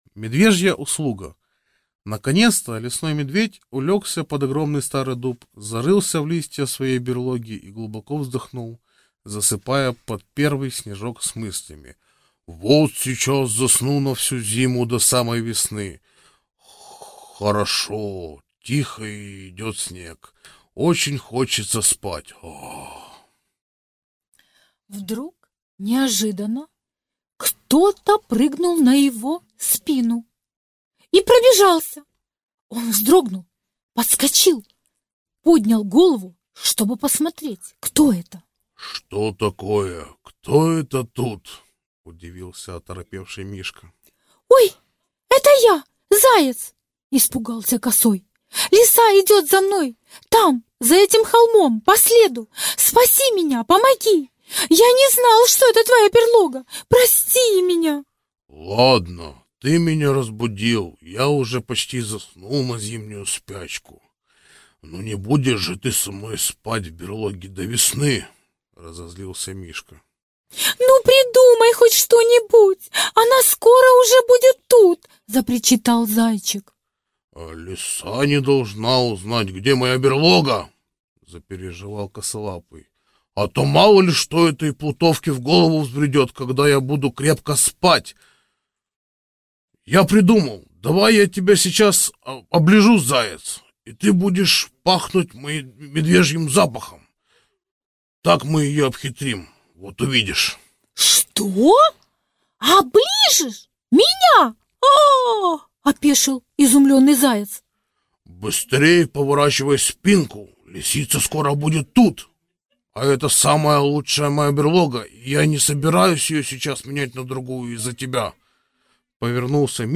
Медвежья услуга - аудиосказка Воскресенской - слушать онлайн